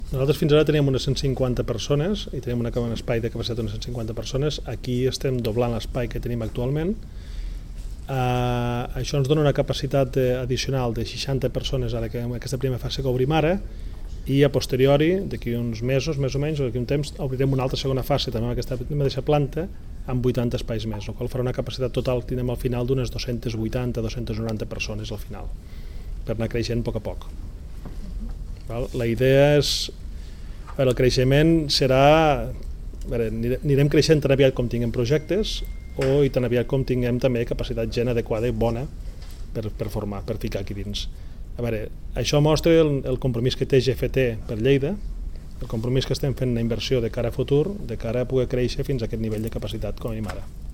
Tall de veu d'Àngel Ros sobre la visita a les noves instal·lacions de GFT al Parc Científic.